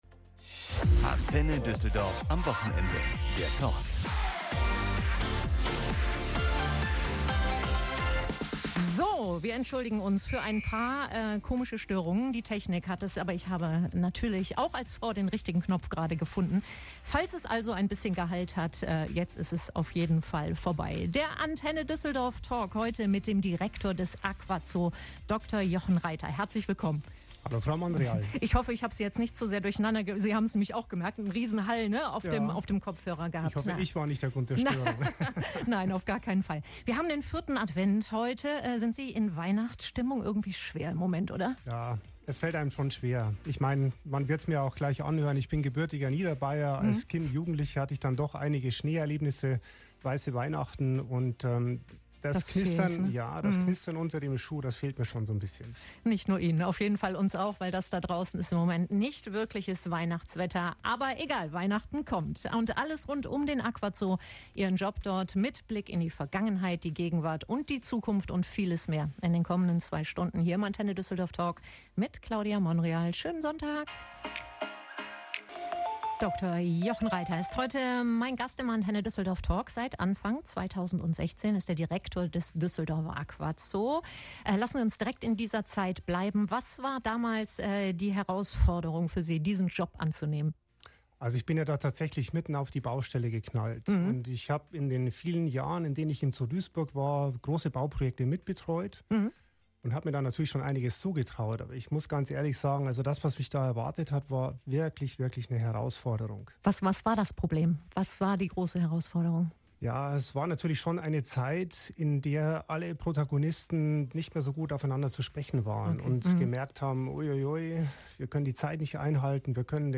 Der Talk